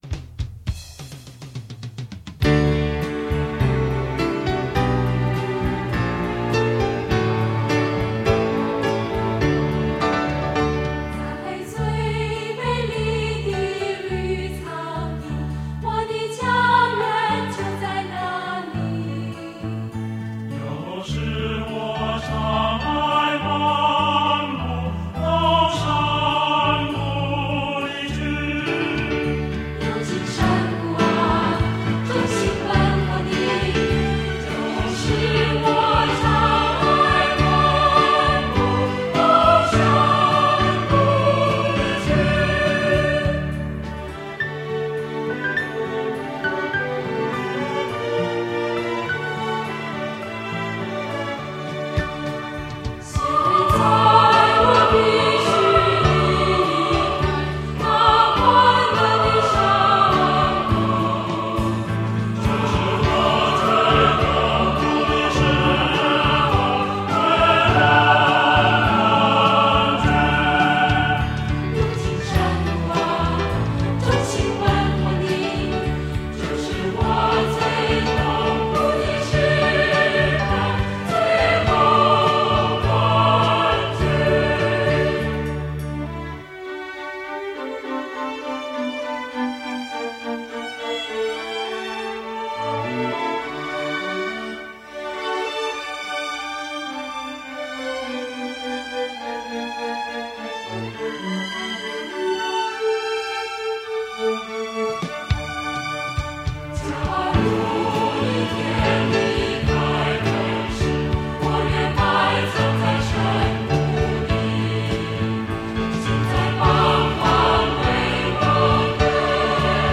以谐合人声的歌咏吟唱方式及更为现代感的编曲手法